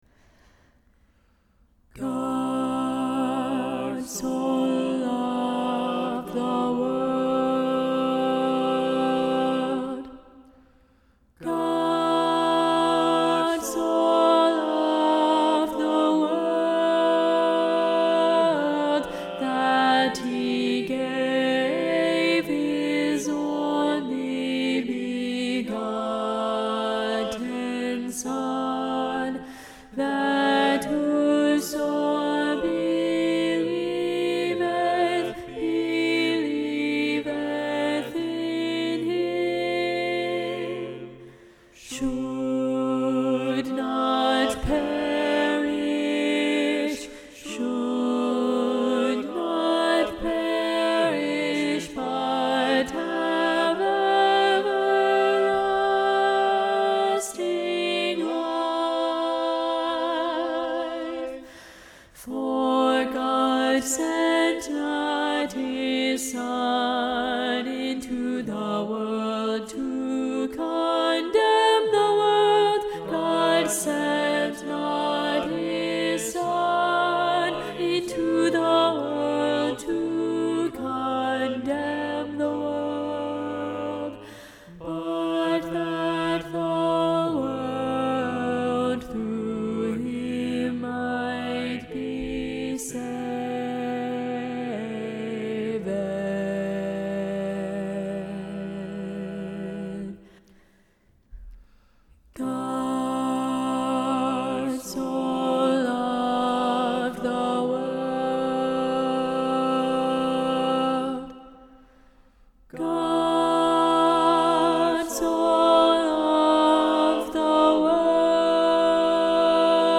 God so Loved the World SATB – Alto Predominant – John StainerDownload